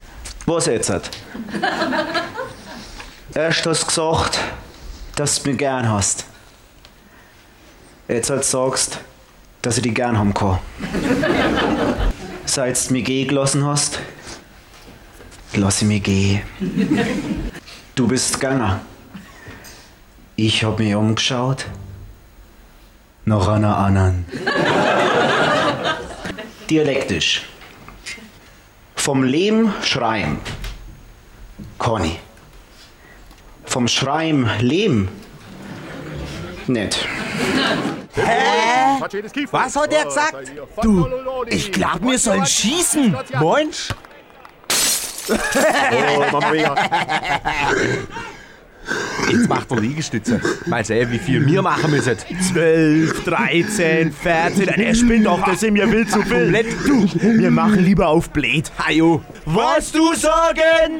Echtes Fränkisch aus Franken (Nürnberg, Bayreuth)
fränkischetexte.mp3